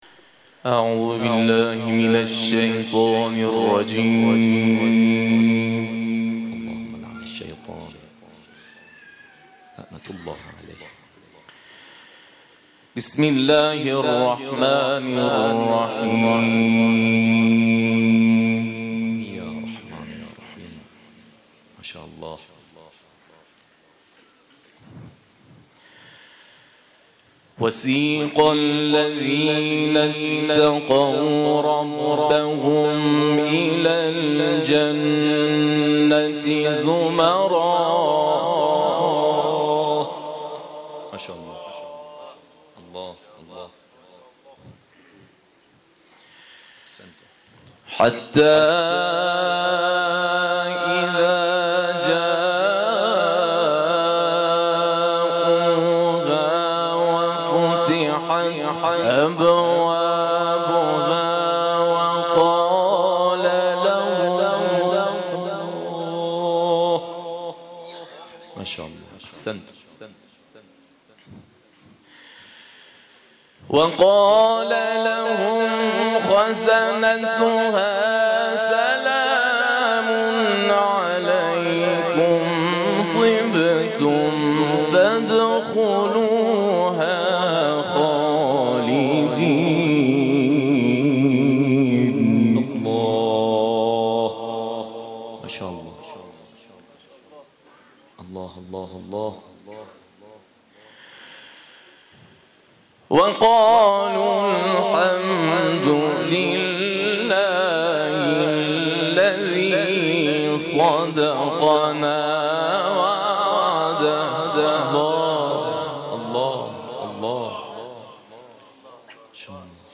تلاوت
در محفل قرآنی محبان امام رضا(ع)، حسینیه قرآن و عترت